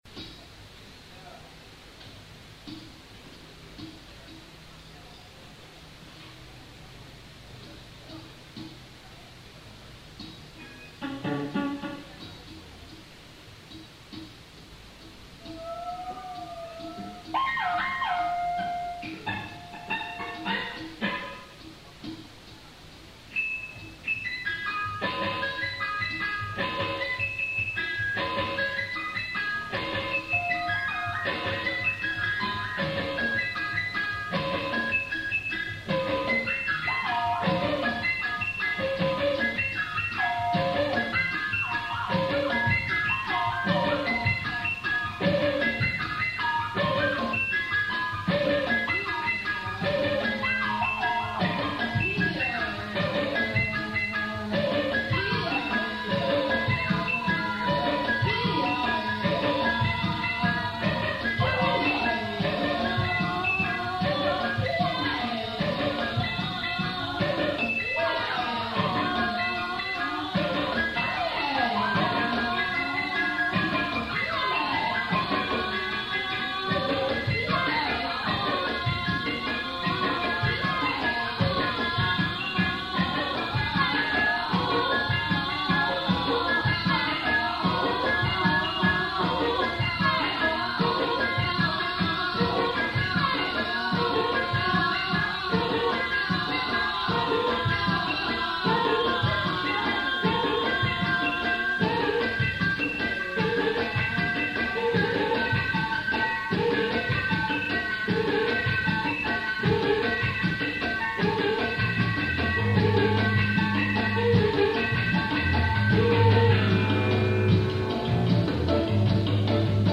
bass
who also plays keys
guitar
drums